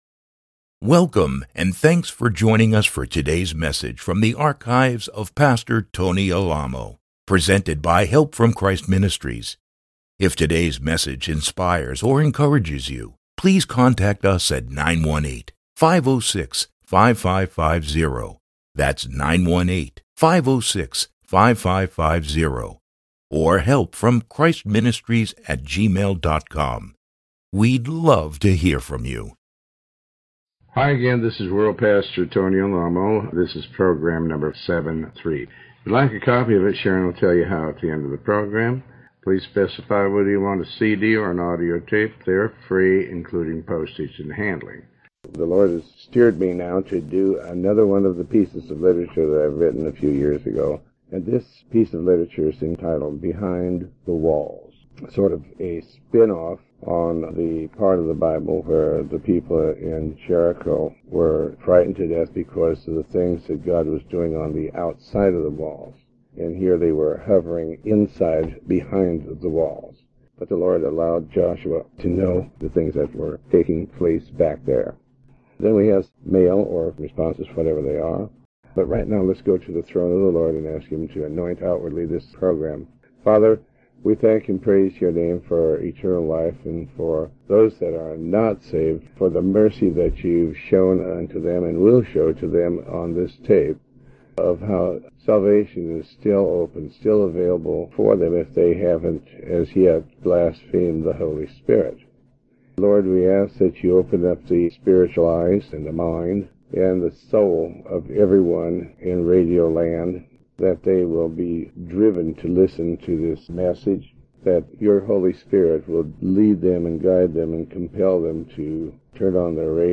Sermon 73A